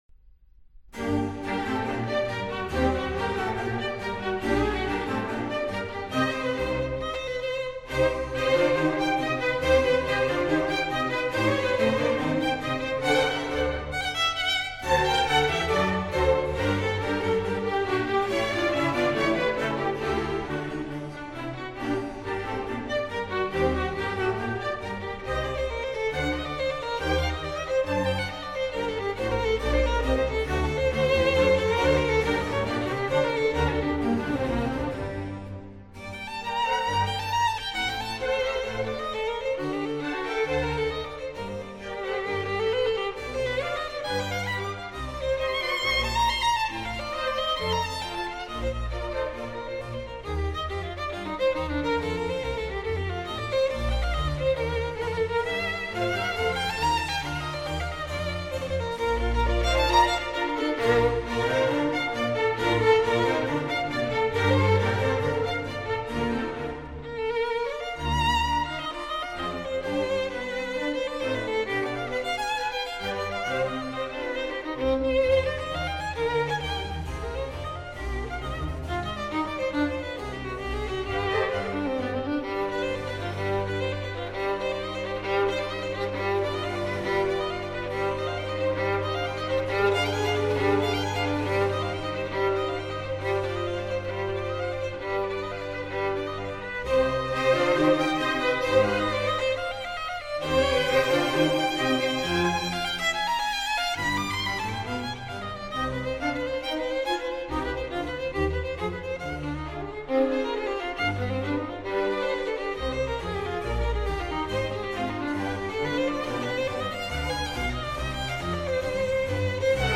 موسیقی کلاسیک: کنسرتو ویولن باخ در سل مینور - Violin Concerto (transcribed from BWV 1056) in G Minor